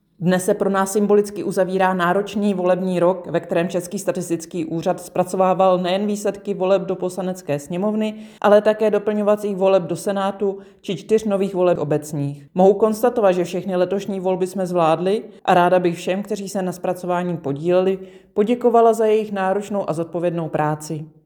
Vyjádření Evy Krumpové, 1. místopředsedkyně Českého statistického úřadu, soubor ve formátu MP3, 833.44 kB